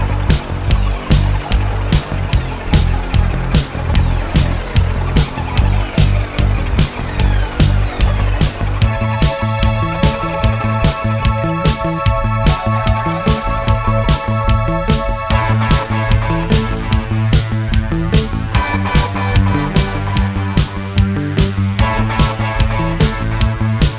Full Length Pop (1980s) 4:59 Buy £1.50